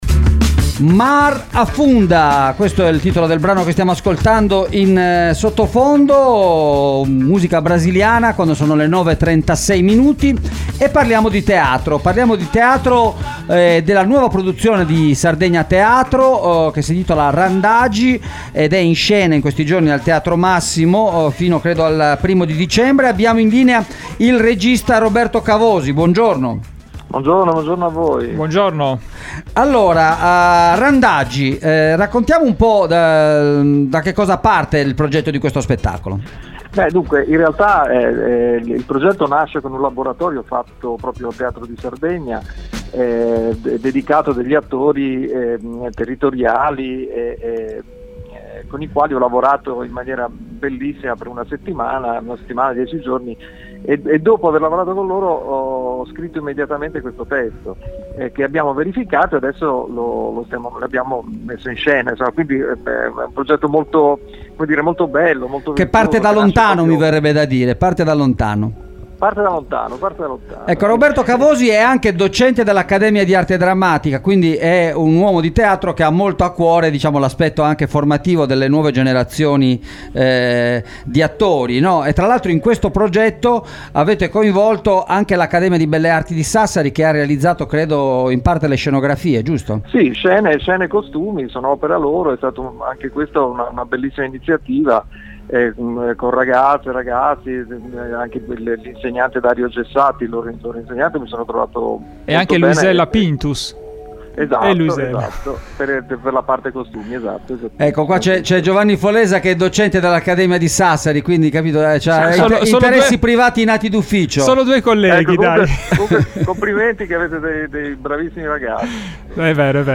“Randagi” al Teatro Massimo – intervista